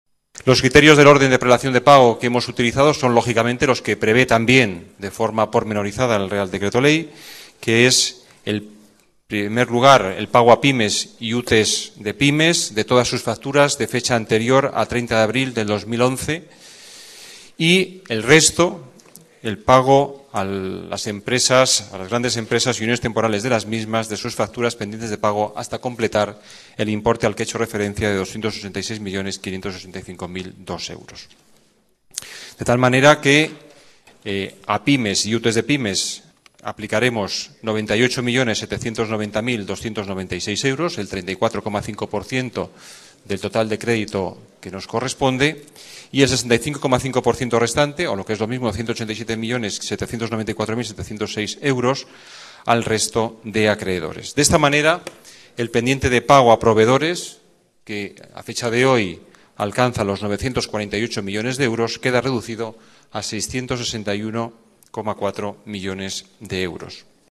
Nueva ventana:Juan Bravo, teniente de alcalde y delegado de Hacienda: Crédito ICO